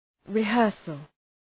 {rı’hɜ:rsəl}